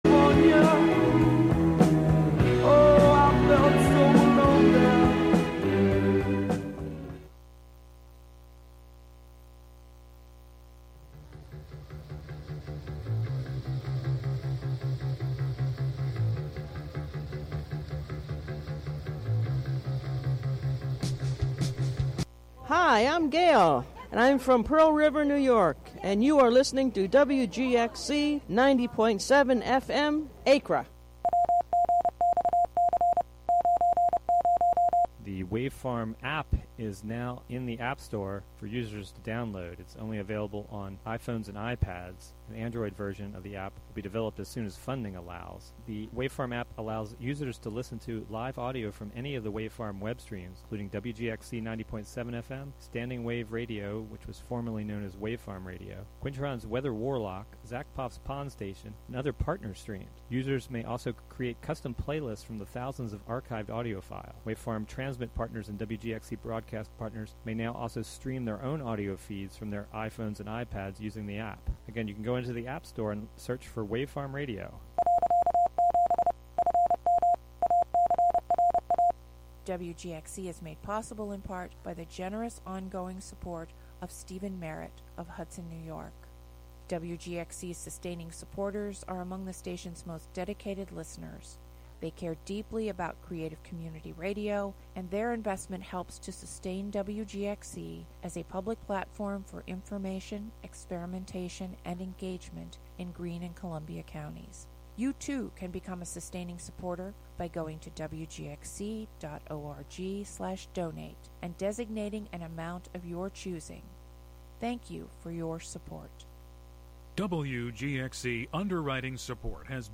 "Home Song #2" is the second in a series of live choral performances by a house in Liverpool, UK
In these hour-long broadcasts ordinarily quiet devices, spaces, and objects within the house are amplified and harmonized into song using sensors and mics then streamed live.
Instead of being silent, a fridge sings of ice and freon, a router sings of packets of data coming and going, a washing machine sings about the love embedded in making mucky things fresh and clean for a new day, and these songs combine to produce a chorus that meets the world through the throat of a live stream over the internet.